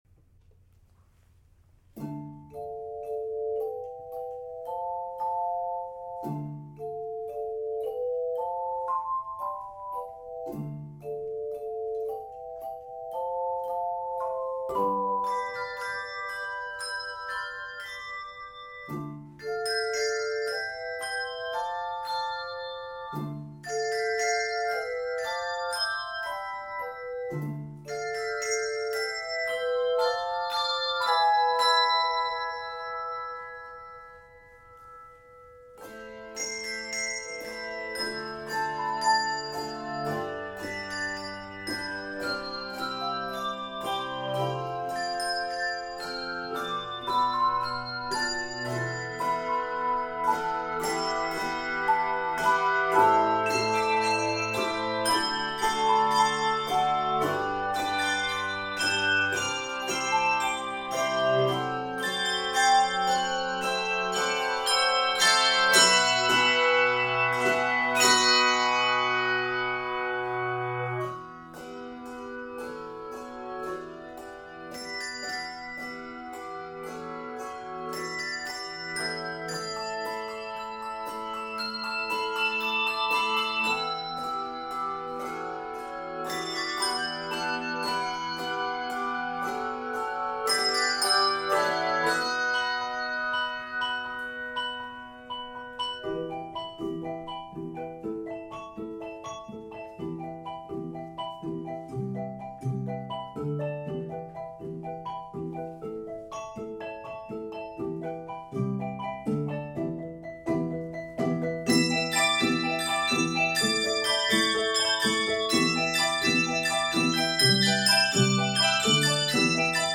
N/A Octaves: 3-6 Level